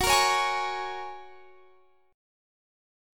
Gbadd9 Chord
Listen to Gbadd9 strummed